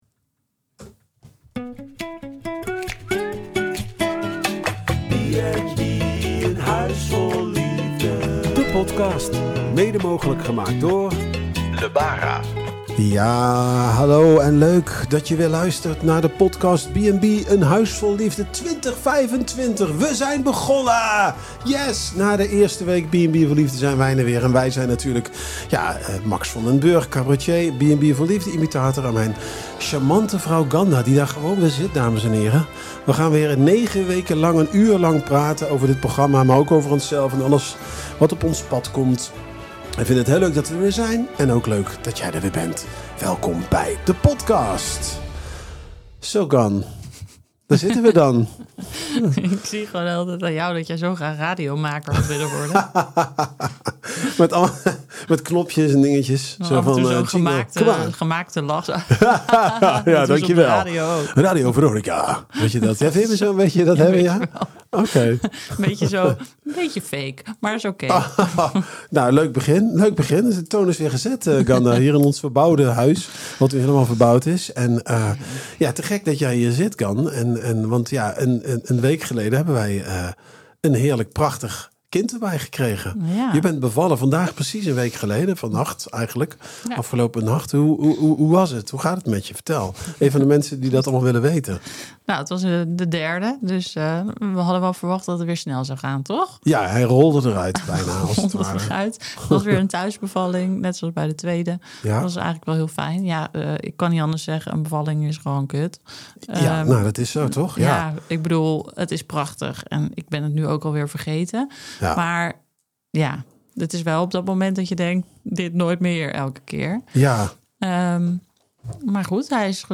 Een uurtje lekker kletsen, met ook nog een heuse quiz erbij!